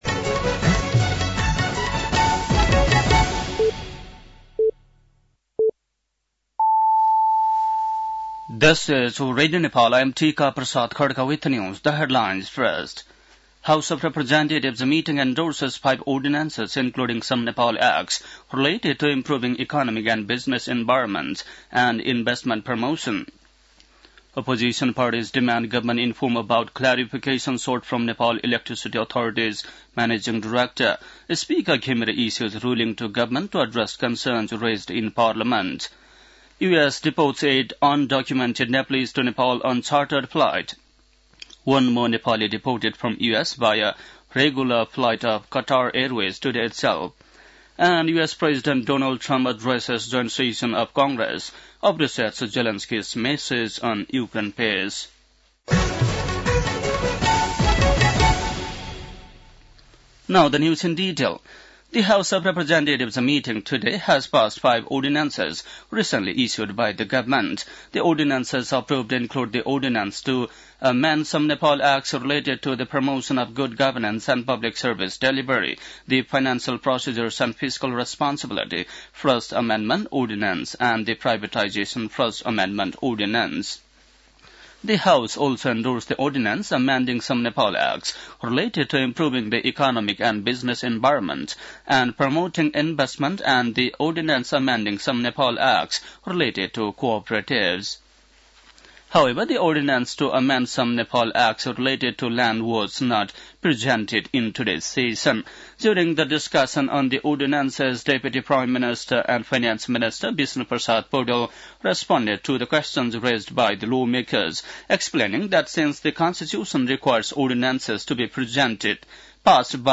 बेलुकी ८ बजेको अङ्ग्रेजी समाचार : २२ फागुन , २०८१
8-pm-english-news-11-21.mp3